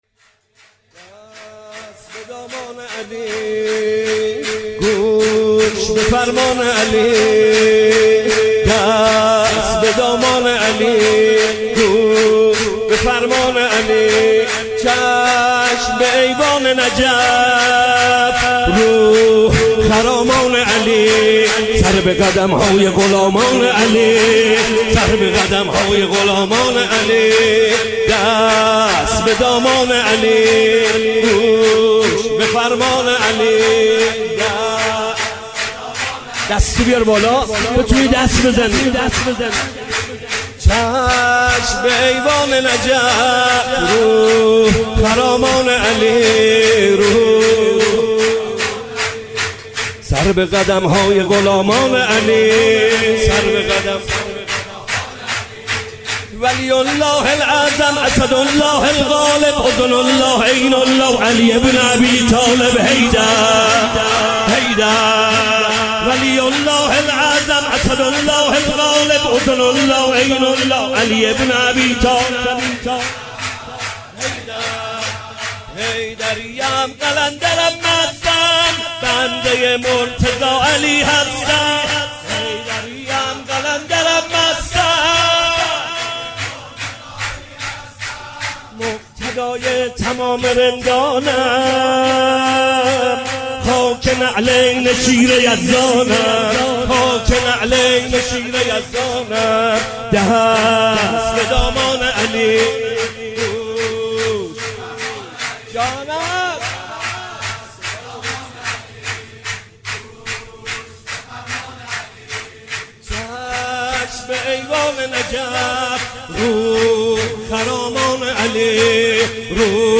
مولودی| گوش به فرمان علی، دست به دامان علی
مولودی خوانی حاج محمدرضا طاهری | جشن میلاد حضرت علی (ع) | 10 فروردین 1397 | هیأت مکتب الزهرا(س) تهران